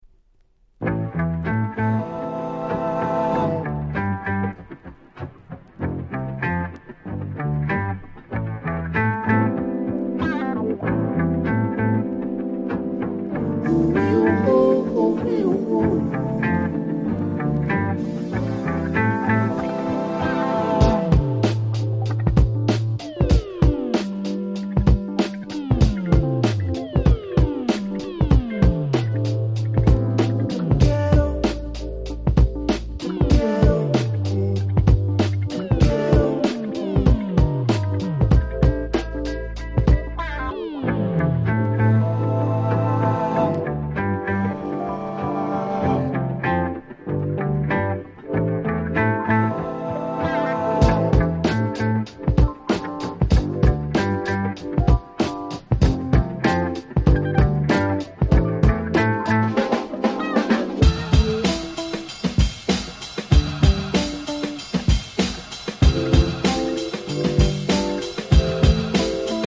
06' Nice Hip Hop !!